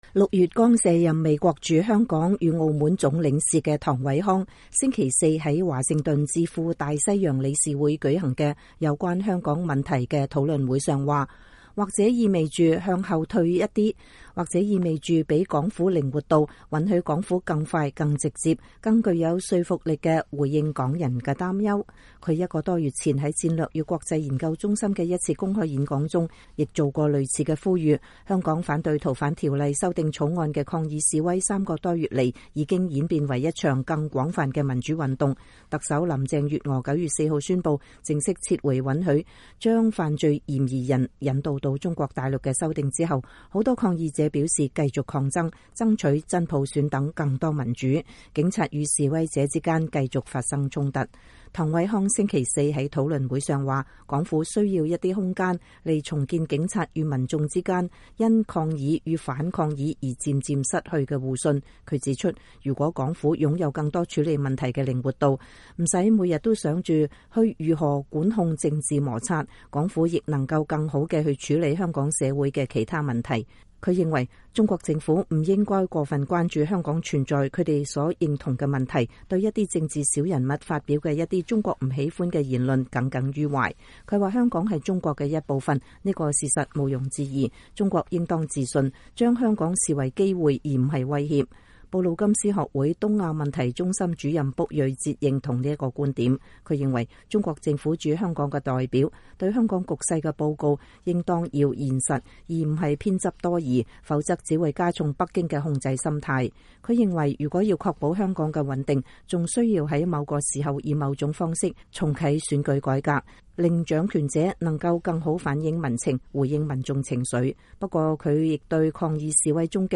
美國前駐香港總領事唐偉康(Kurt Tong)在大西洋理事會有關香港問題的討論會上發表演講，2019年9月12日。